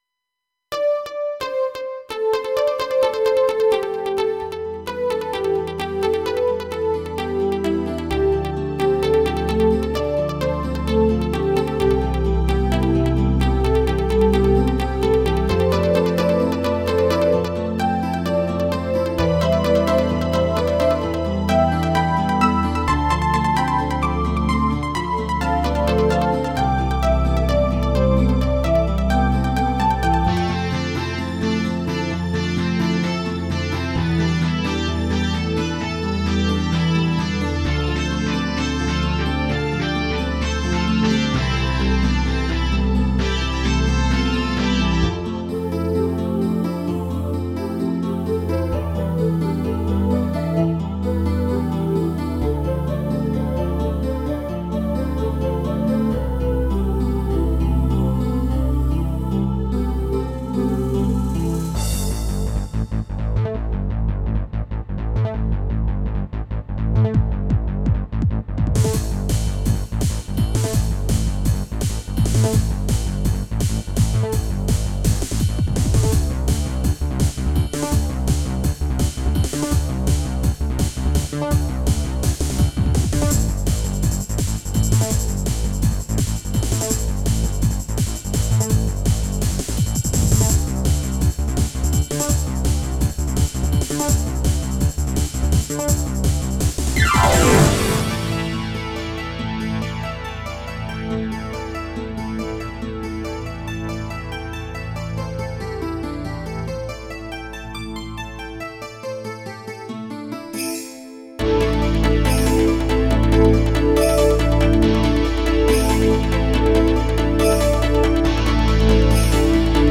和風シンセロック的な曲です。変拍子もあるよ。